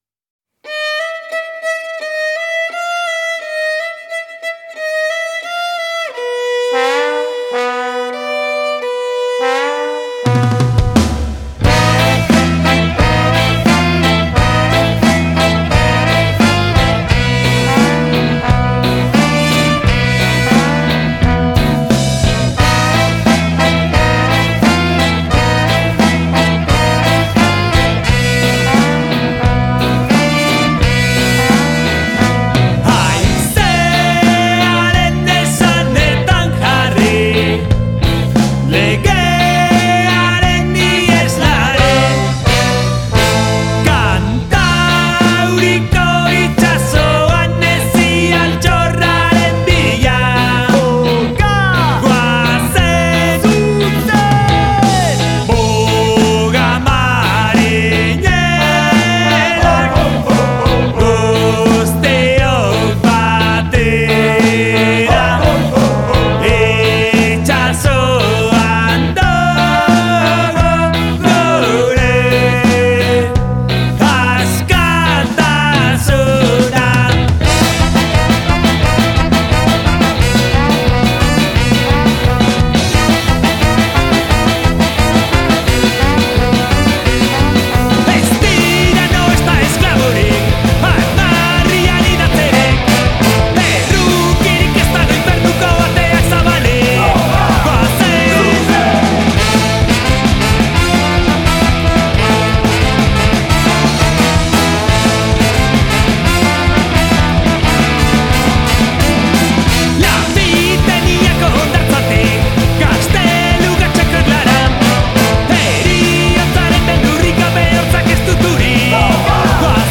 voz principal